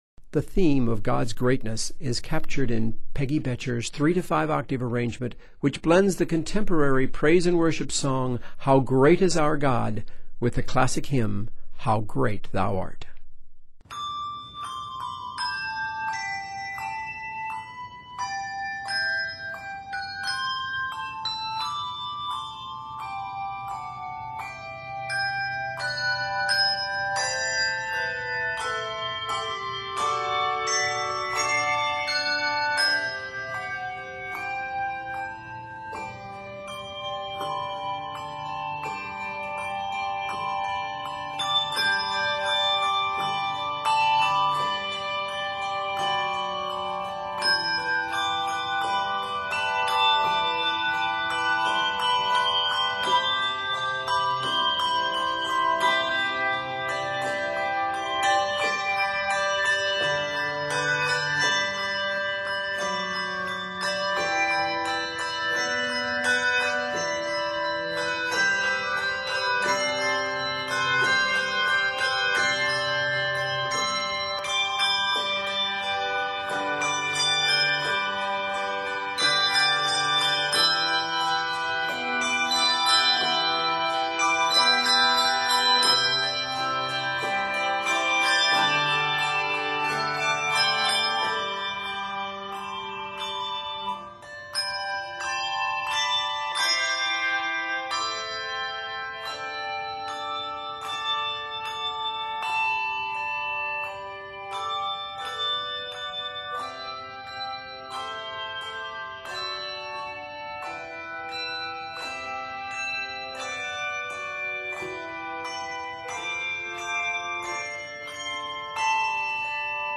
3-5 octave arrangement